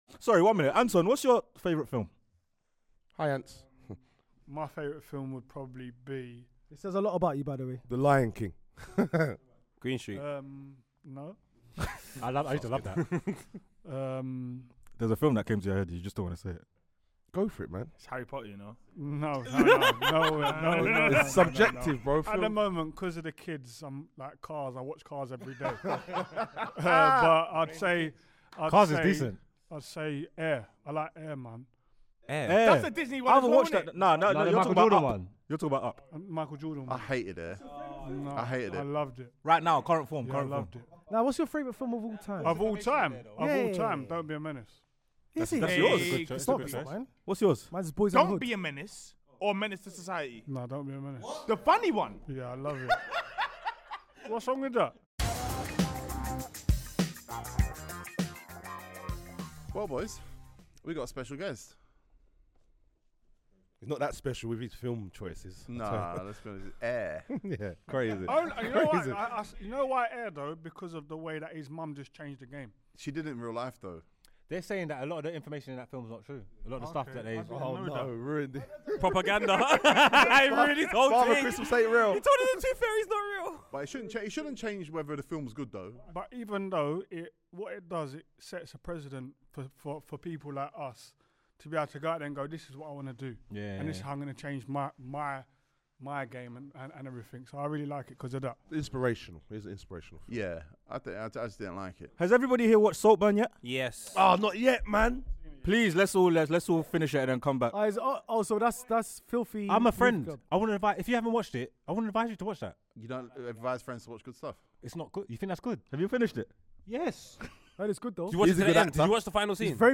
This one has been simmering for a while and the time is definitely right for ANTON FERDINAND to come through the FILTHYFELLAS studio